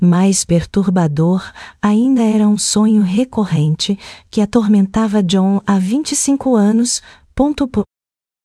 Áudios Gerados - Genesis TTS